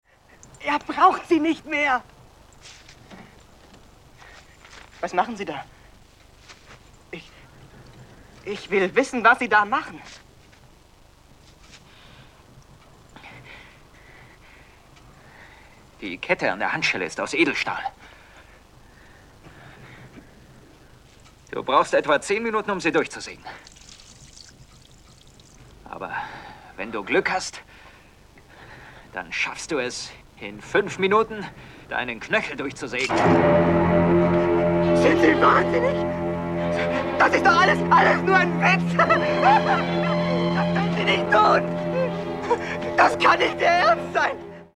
O-Ton der Szene